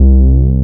17BASS01  -L.wav